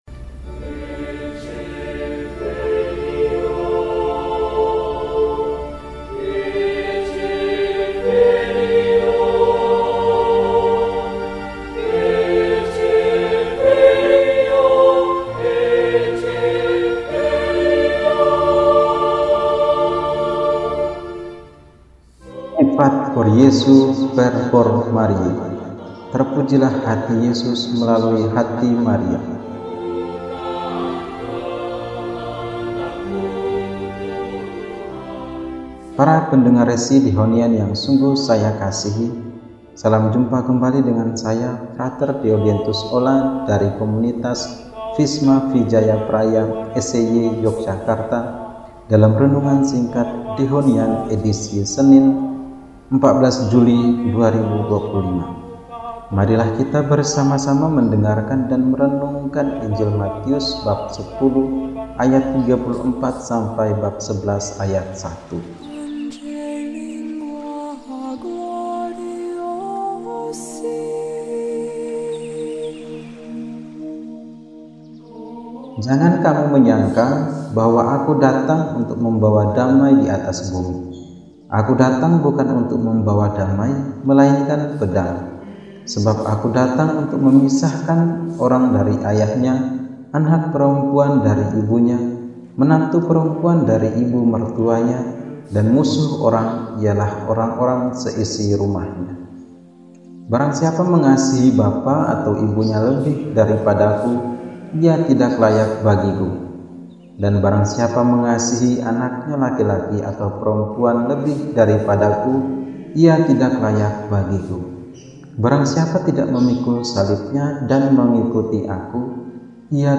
Senin, 14 Juli 2025 – Hari Biasa Pekan XV – RESI (Renungan Singkat) DEHONIAN